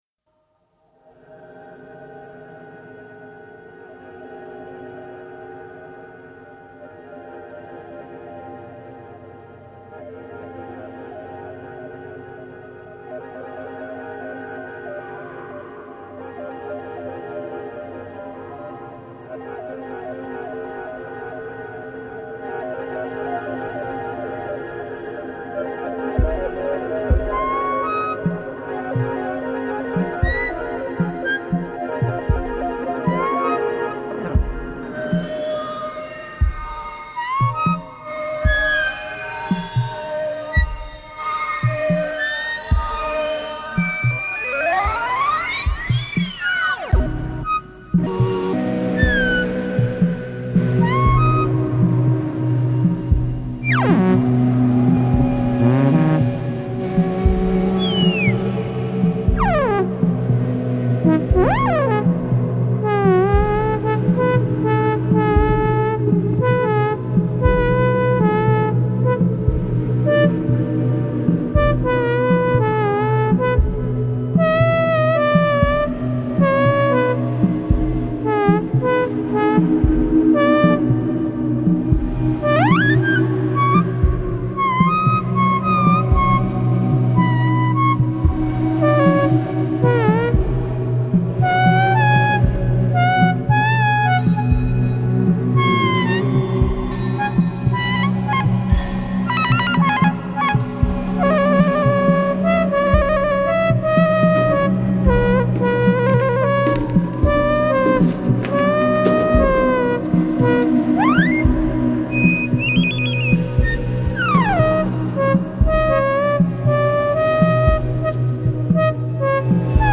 prolifique producteur canadien de musique électronique.